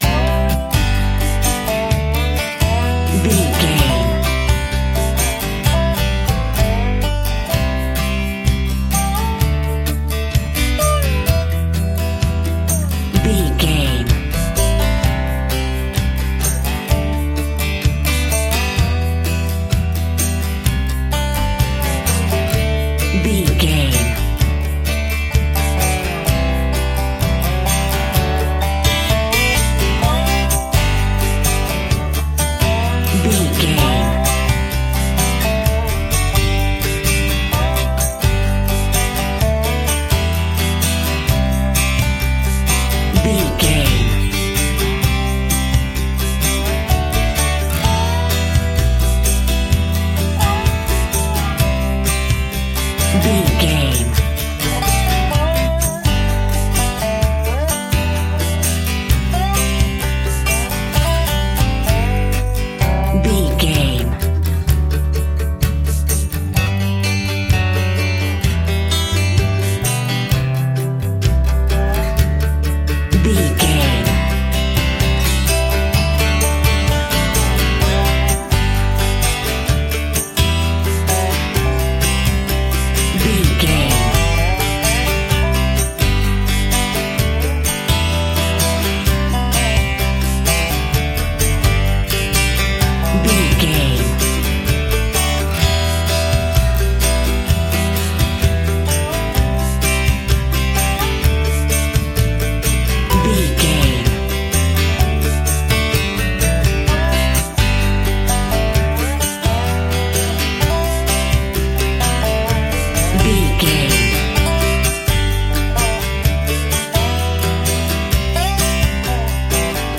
Uplifting
Ionian/Major
acoustic guitar
bass guitar
banjo